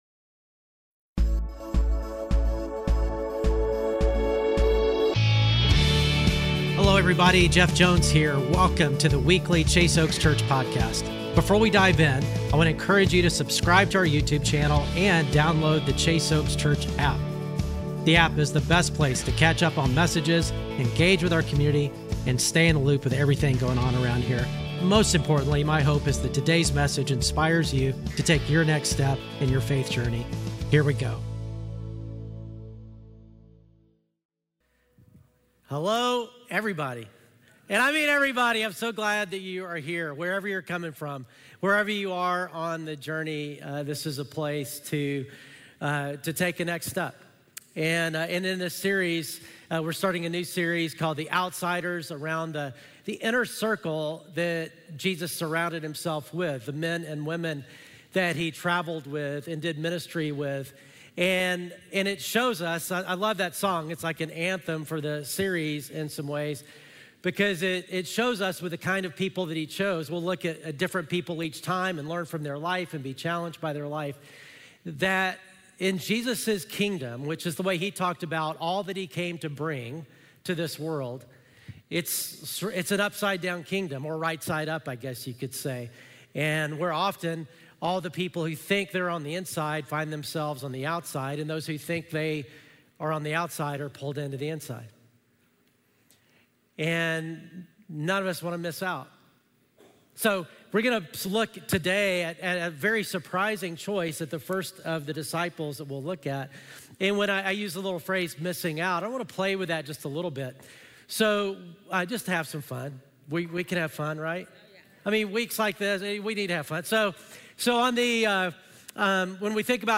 Weekly Sermons at Chase Oaks Church in Plano, Texas